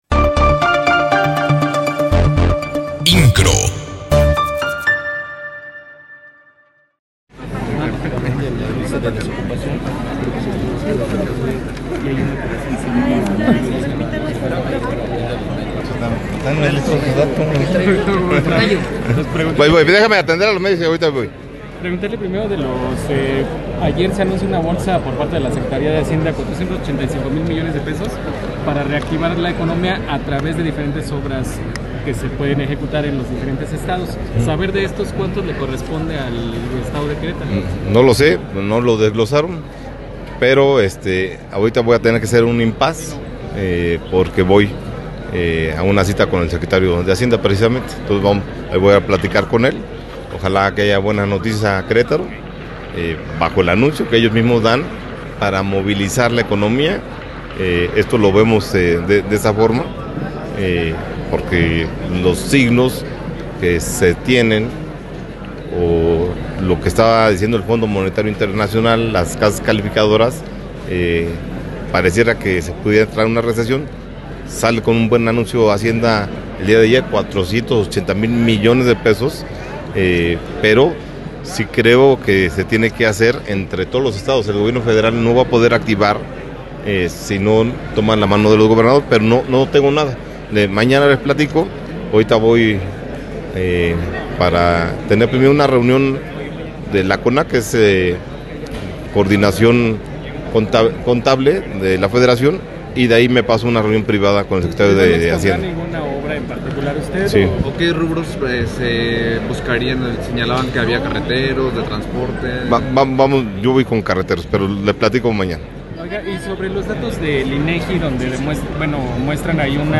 Entrevista con Francisco Domínguez Servién Gobernador del Estado de Querétaro